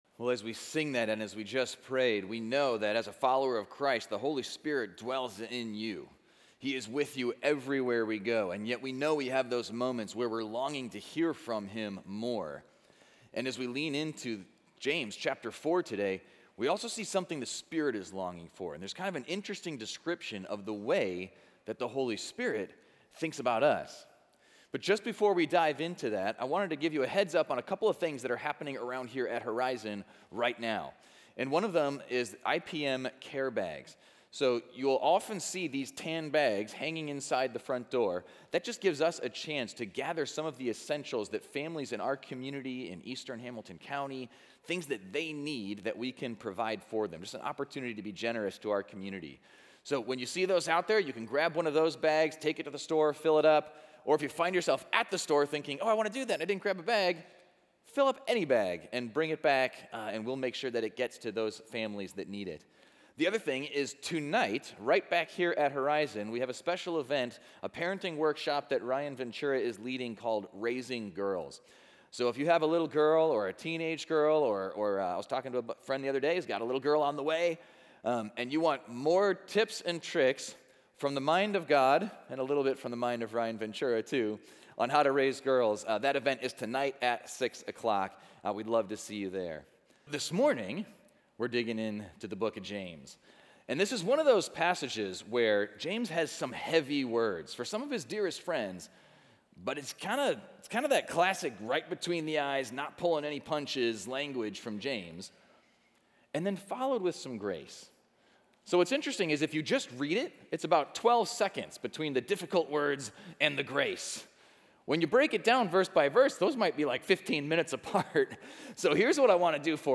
Equipping Service / James: Live and Love Wisely / Humble Trust